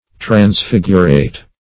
Transfigurate \Trans*fig"u*rate\